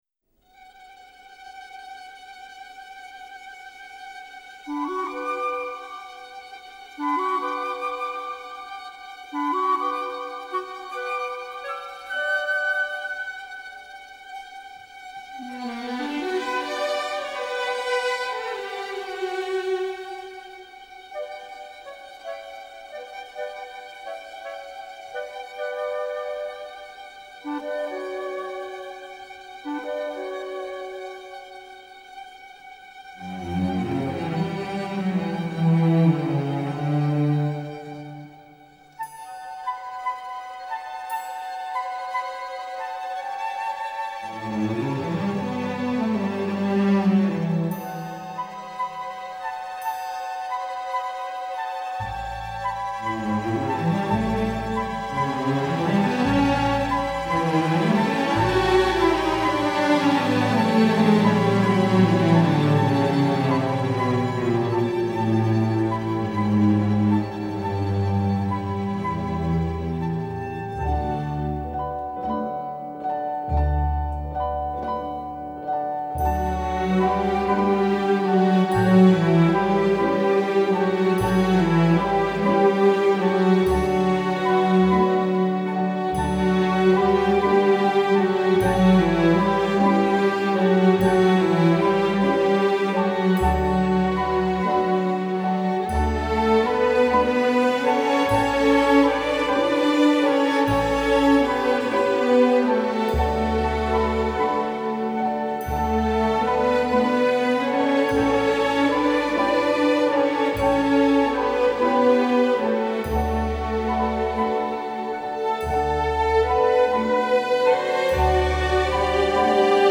barcarole.mp3